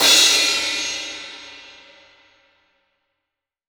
Index of /90_sSampleCDs/AKAI S6000 CD-ROM - Volume 3/Crash_Cymbal1/16-17_INCH_CRASH
DRY 17CRS1-S.WAV